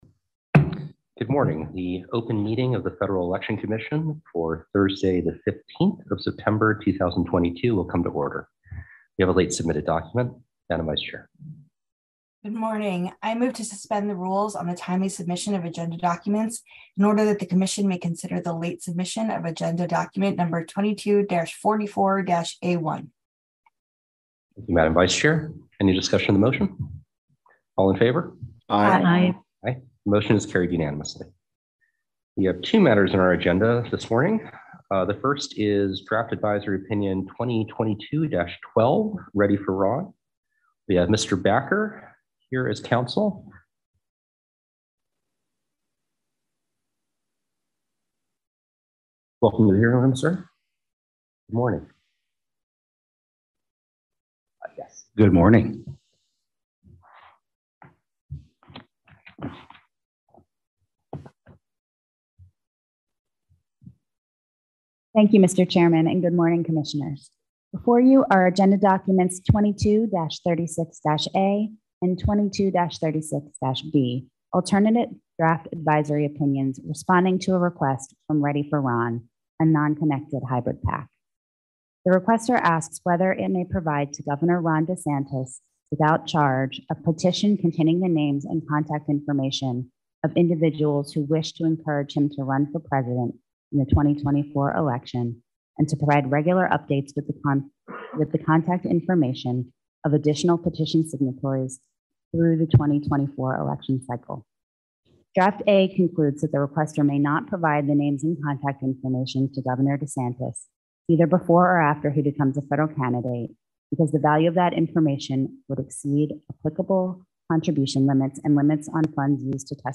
September 15, 2022 open meeting of the Federal Election Commission